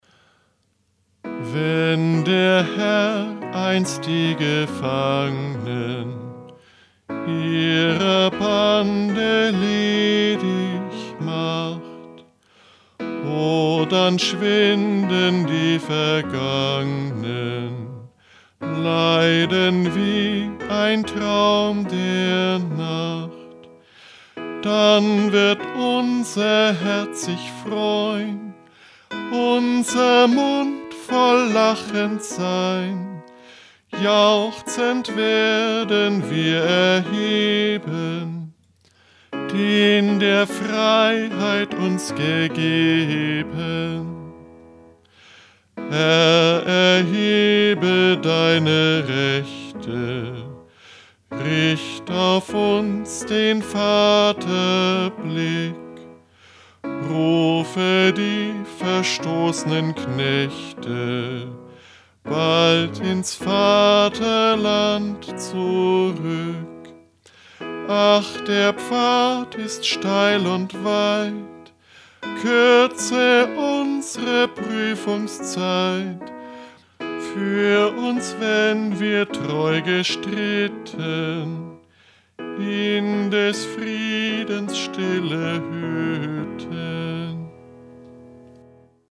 Liedvortrag